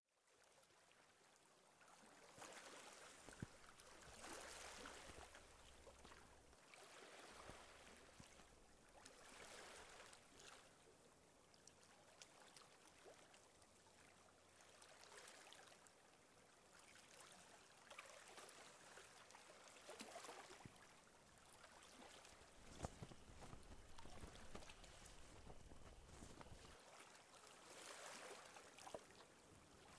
Sounds of Nature (30 min.)